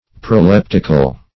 Proleptic \Pro*lep"tic\, Proleptical \Pro*lep"tic*al\, a. [Gr.